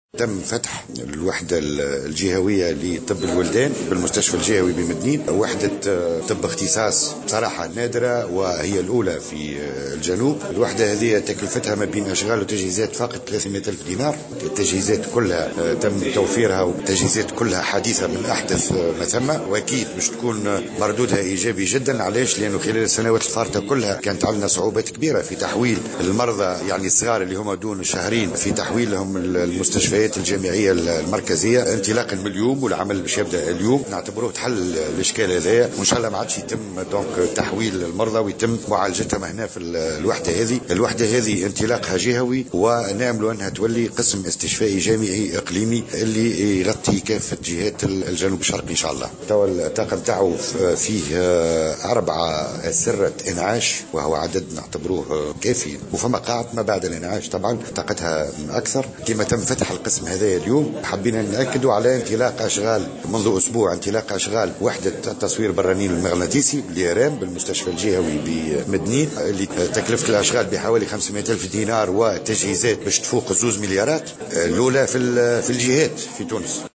وقال المدير الجهوي للصحة بمدنين، الطيب شلوف في تصريح لمراسلة "الجوهرة أف أم" إن هذه الوحدة تعتبر الأولى في الجنوب، مشيرا إلى أن كلفة الإنجاز فاقت 300 ألف دينار.